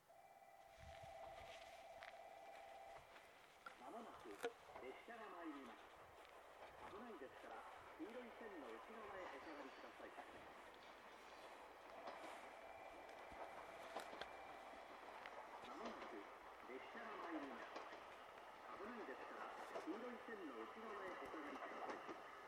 ２番のりば日豊本線
接近放送普通　宮崎行き接近放送です。